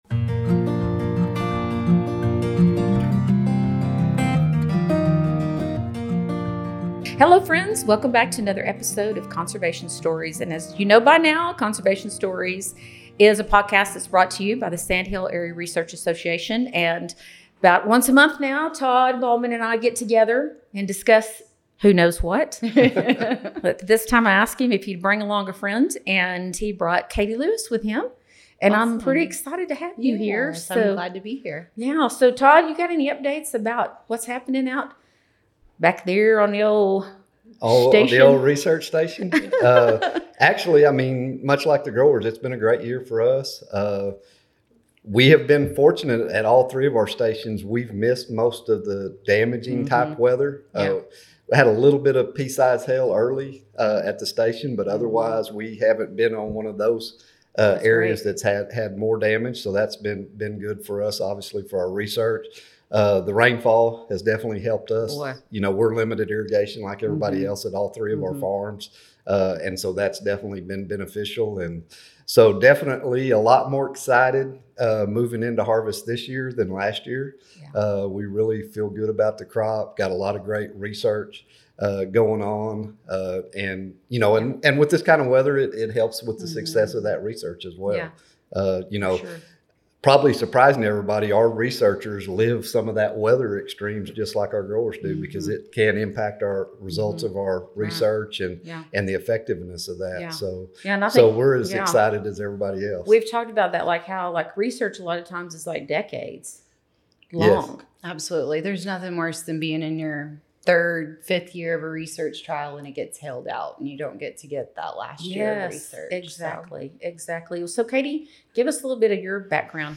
Conservation Stories is presented by The Sandhills Area Research Association (SARA). Subscribe now to hear all the interviews.